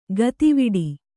♪ gati viḍi